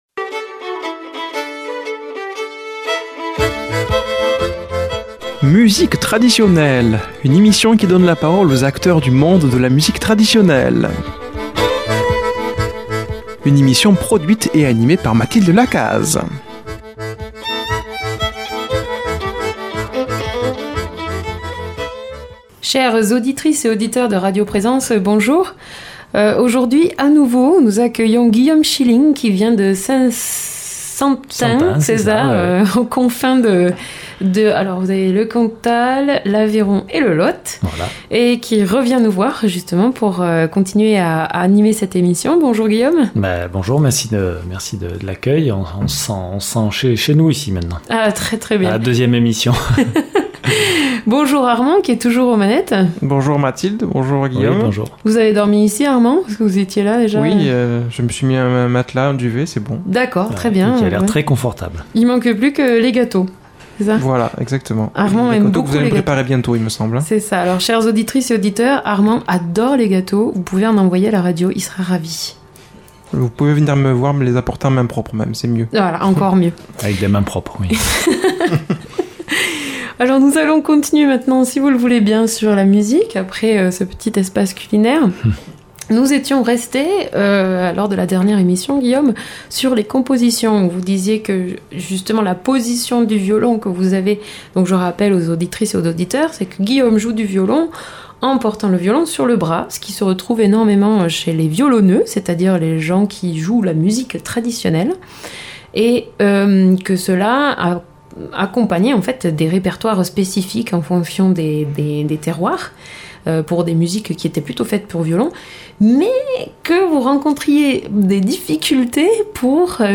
l’entretien avec le violoniste